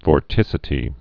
(vôr-tĭsĭ-tē)